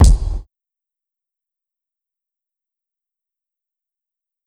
Kick (Couch).wav